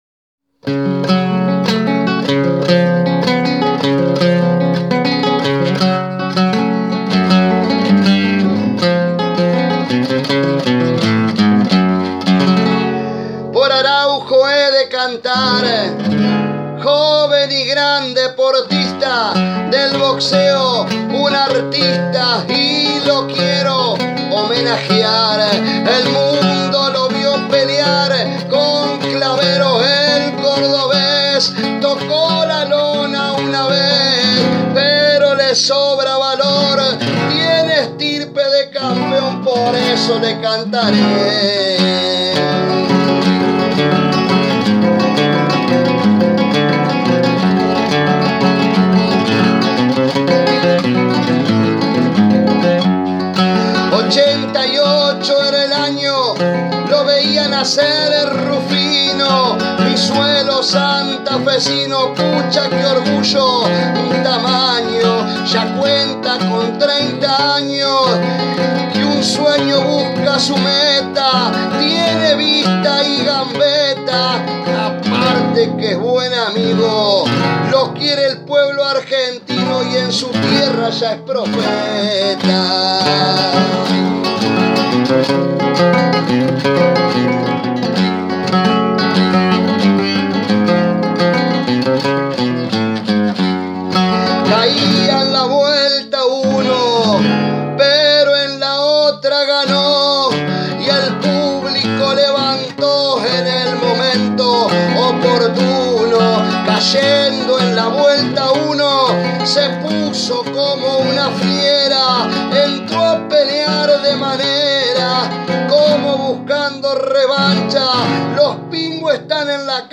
payador rosarino, homenajeó con su improvisación